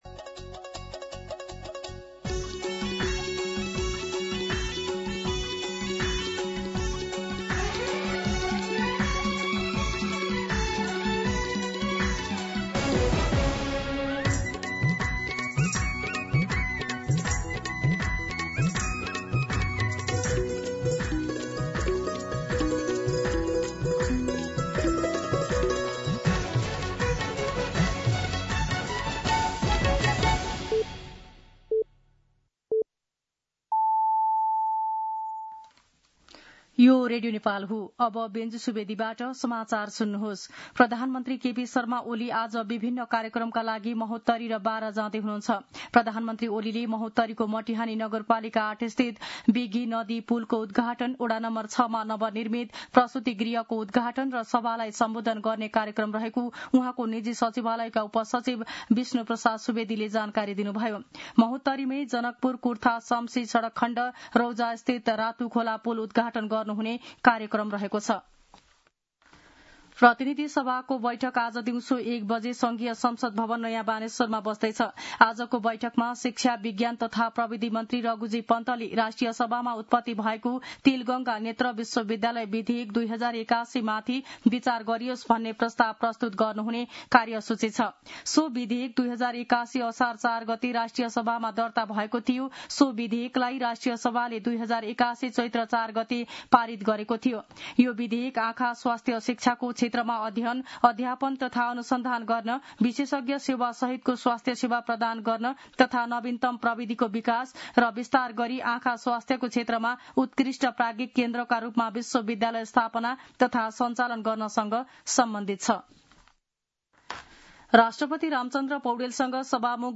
मध्यान्ह १२ बजेको नेपाली समाचार : १८ पुष , २०२६